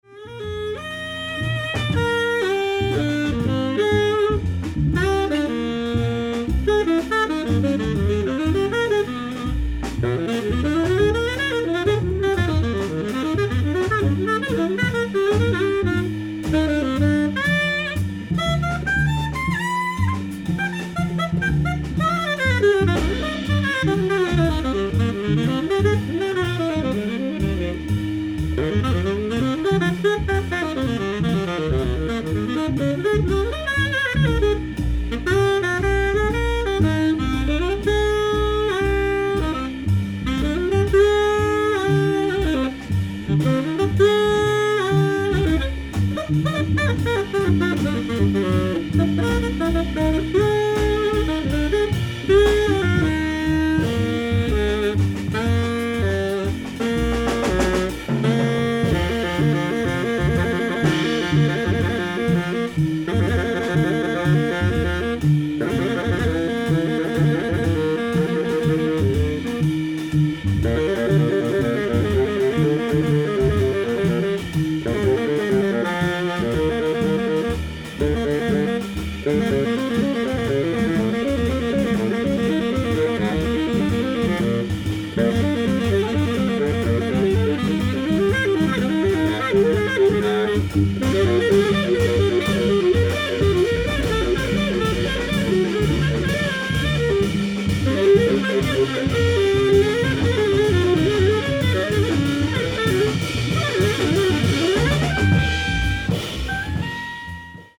ディスク１＆２：ライブ・アット・ジャズ・スタンダード、ニューヨーク 07/14/2016
※試聴用に実際より音質を落としています。